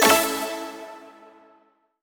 confirm-selection.wav